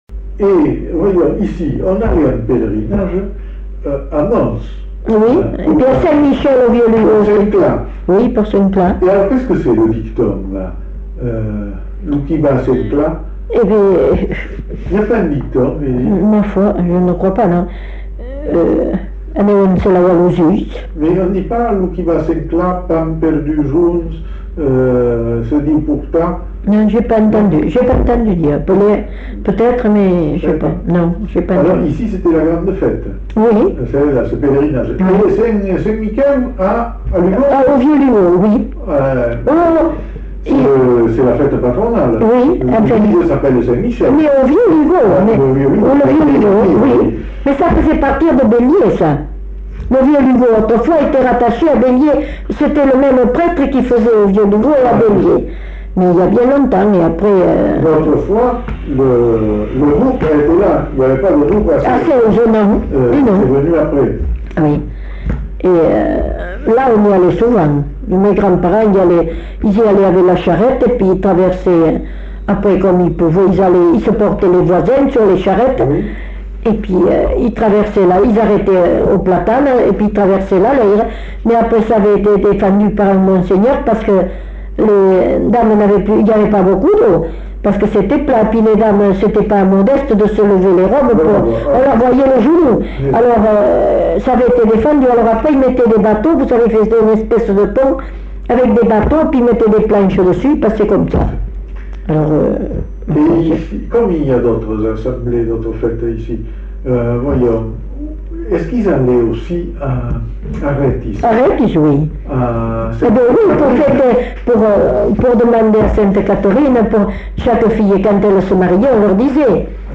Lieu : Belin-Beliet
Genre : témoignage thématique